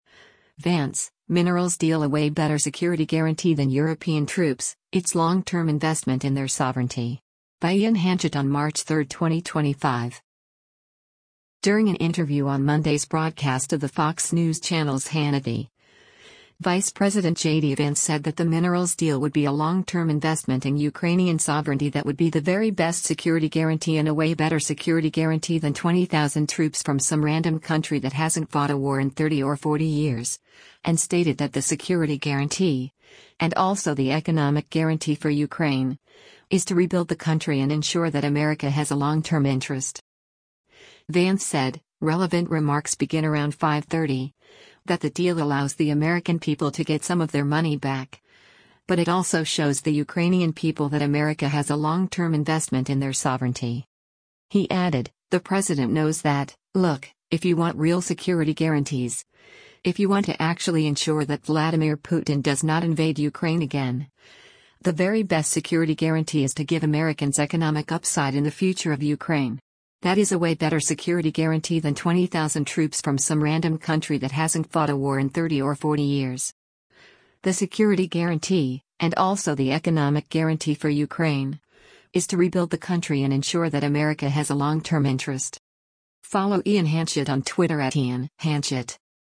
During an interview on Monday’s broadcast of the Fox News Channel’s “Hannity,” Vice President JD Vance said that the minerals deal would be “a long-term investment” in Ukrainian sovereignty that would be “the very best security guarantee” and a “way better security guarantee than 20,000 troops from some random country that hasn’t fought a war in 30 or 40 years.”